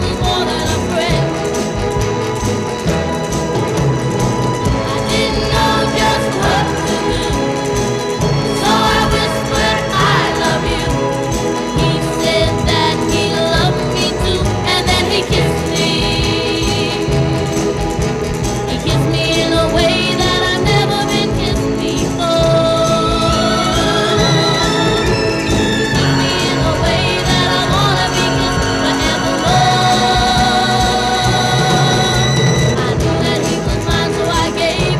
Жанр: Поп музыка / Рок / R&B / Соул